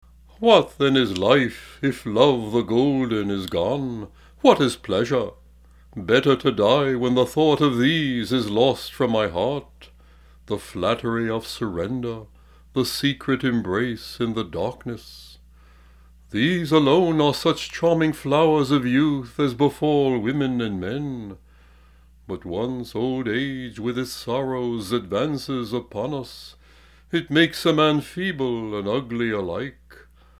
The Living Voice of Greek Literature: A Recital of Greek Poetry - Selections from Homer's Iliad Part 1